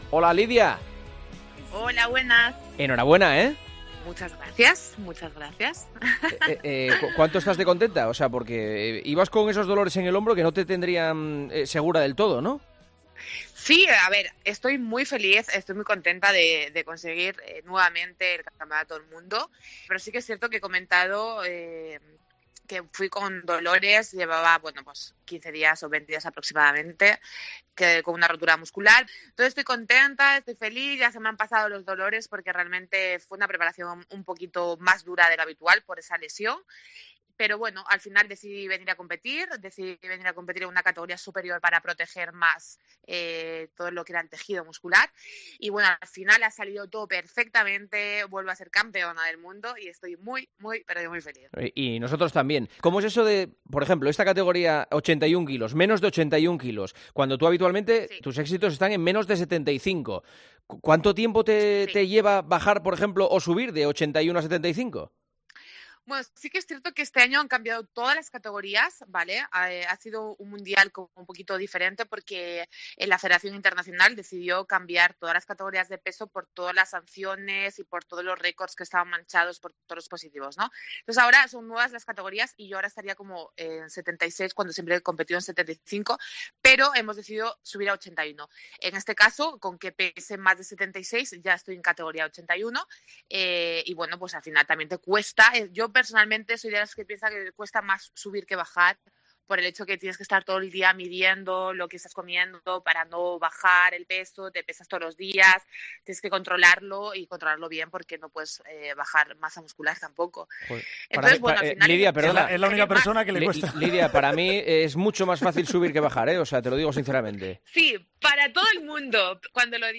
La campeona del mundo de halterofilia habló con Juanma Casataño: “Llevaba varios días con molestias, pero ha salido todo muy bien”.
Lydia Valentín atendió la llamada de El Partidazo de COPE, tan sólo horas después de haberse proclamado campeona del Mundo -y ya es bicampeona mundial-, en una categoría nueva para ella: "Estoy muy feliz de conseguir el Campeonato del Mundo. Comenté que llevaba varios días con dolores por una rotura muscular, pero al final decidí competir, incluso con una categoría superior para superar esas molestias y ha salido todo muy bien", comentó feliz.